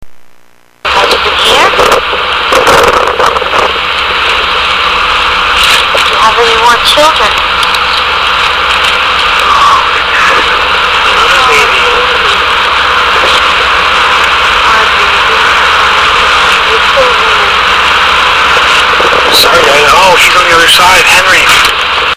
Pictures and E.V.P.'s